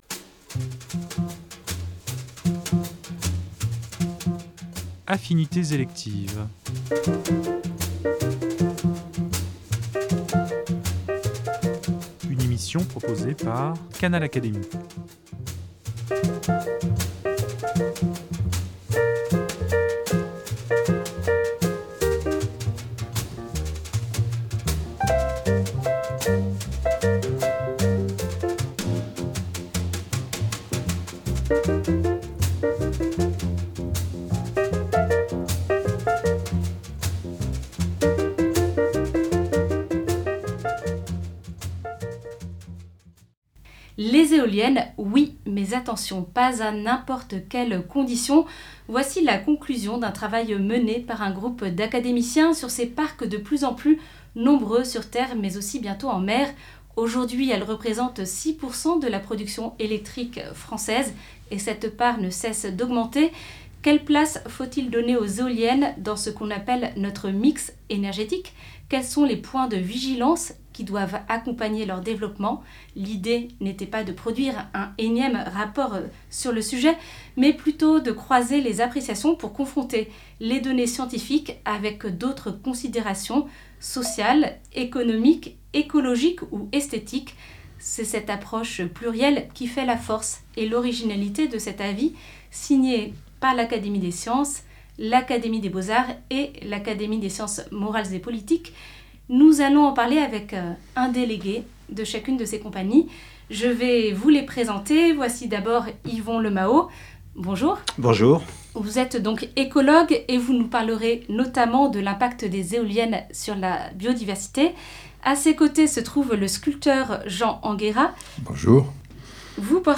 Nous allons en parler avec leurs trois délégués